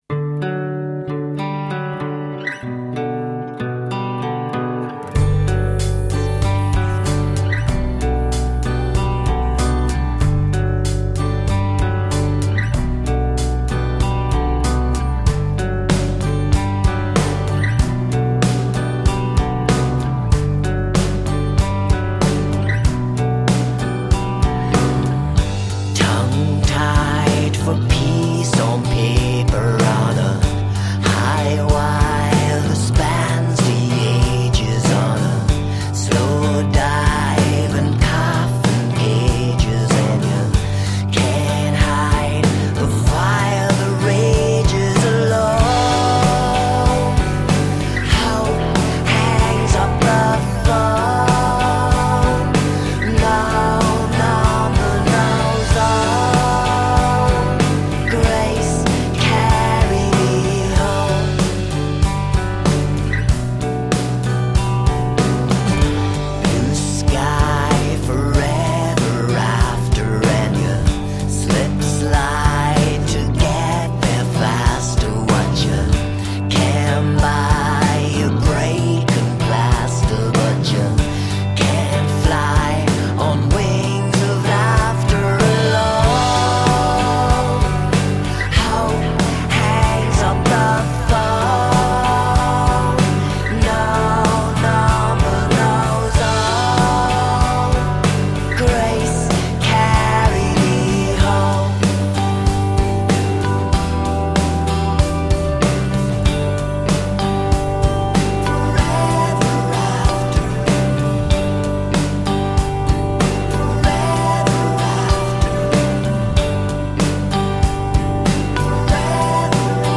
Soul searching irish/french indie rock.
Tagged as: Alt Rock, Rock